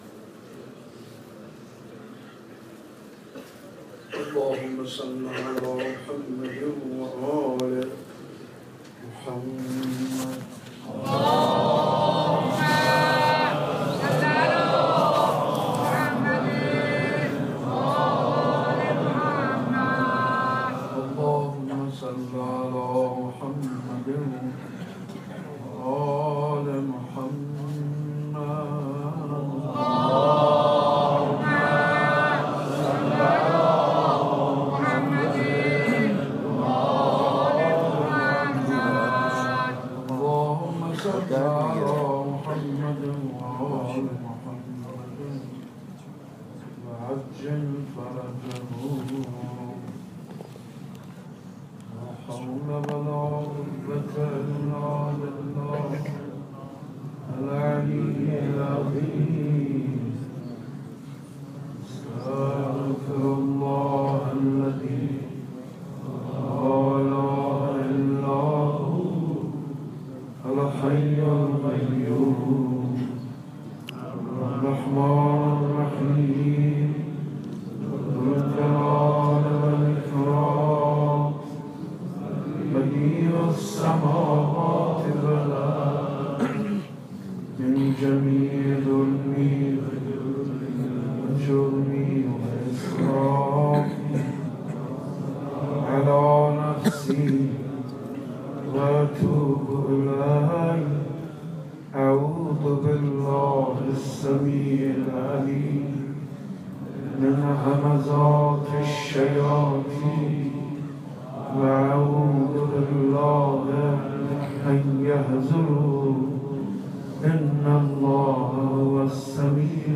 صوت مداحی و روضه خوانی حاج منصور ارضی ایام فاطمیه دوم در صنف لباس فروش ها منتشر شد.